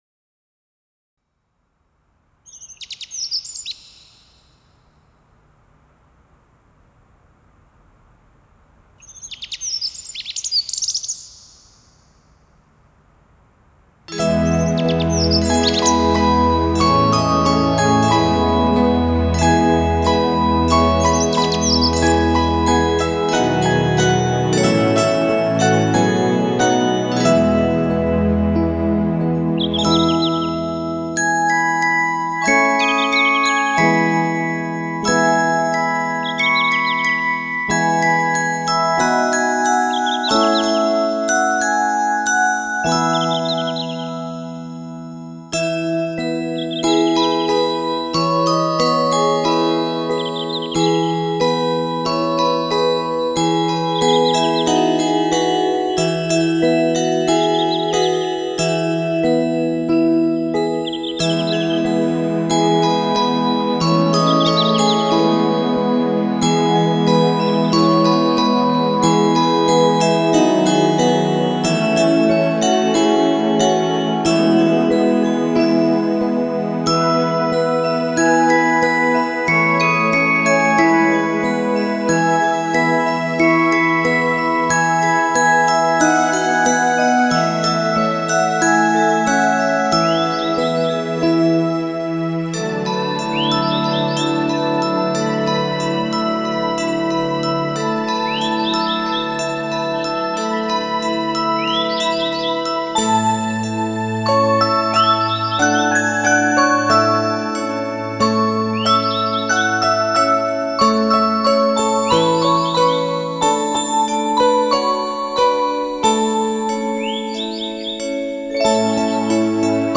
放进水晶般的音符里，沉浸、涤荡、漂净…
梵音的澄澈、轻扬，让心的忧容褪色、雾化，